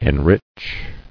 [en·rich]